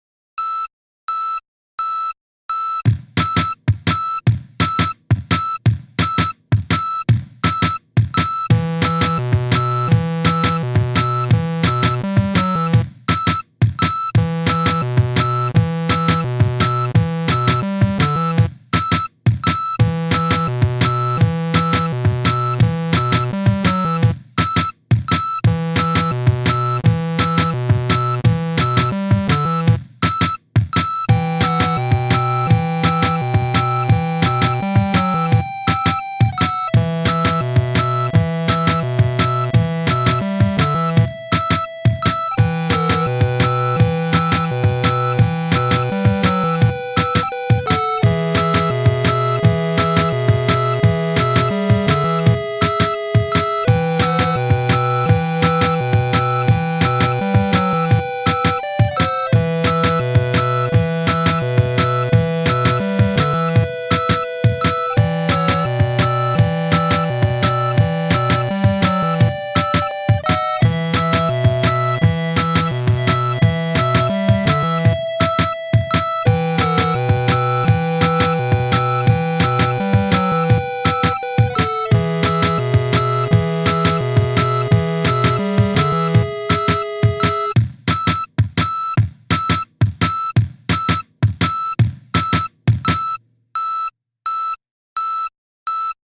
a truck in reverse A very repetative short little 8-bit-sounding-ish "song" that kinda sounds like the beeping of a truck in reverse.